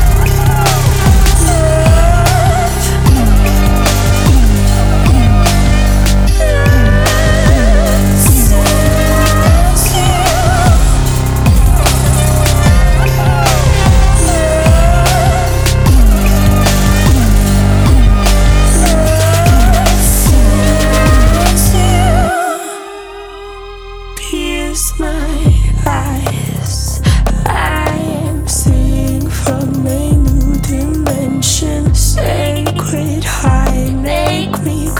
Жанр: Поп музыка / Альтернатива
Alternative, Pop